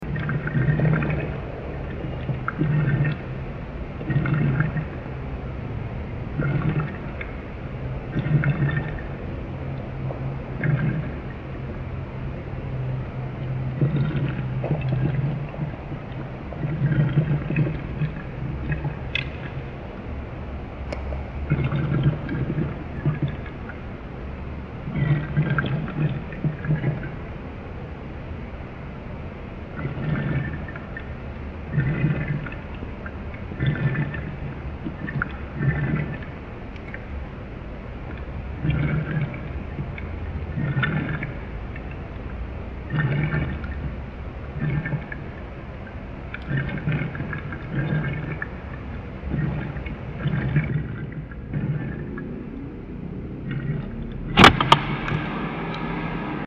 Freezer from the inside
Tags: Travel Argentina Sounds of Argentina Vacation Buenos Aires